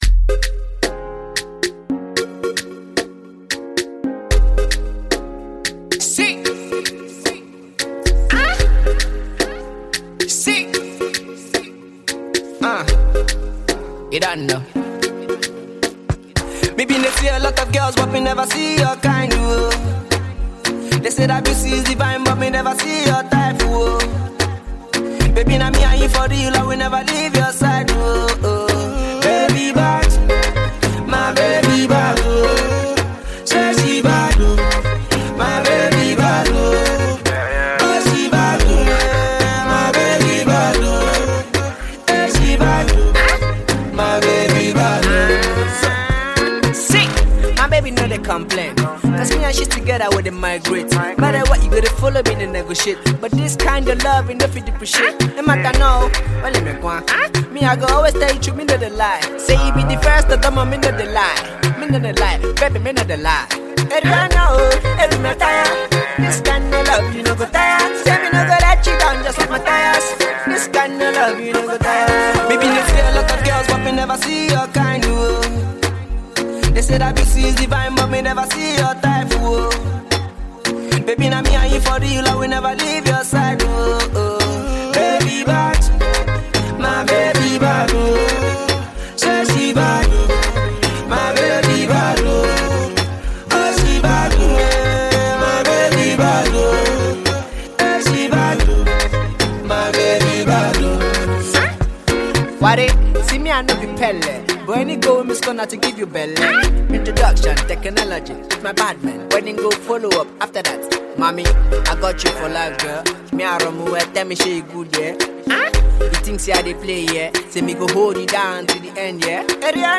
branching out to his female audience with this love track.